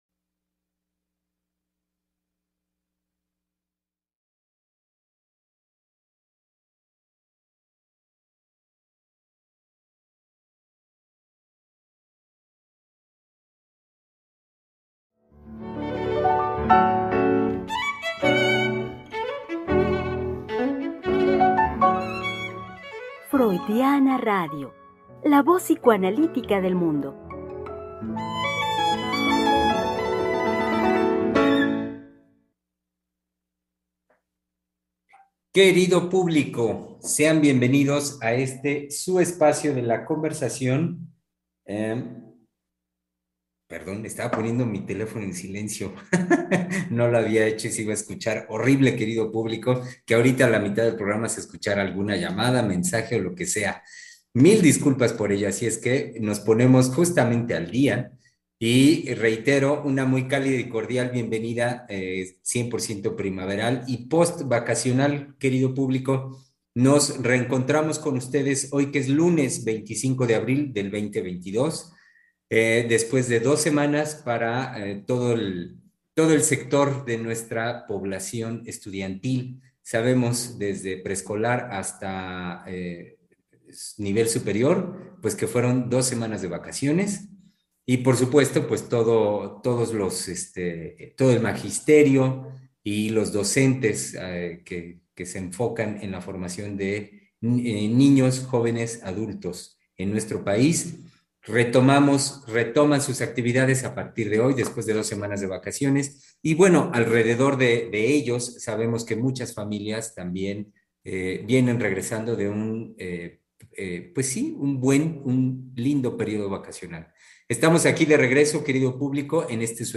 Psicoanalistas-hablando-de-la-vida-cotidiana.25-abril.mp3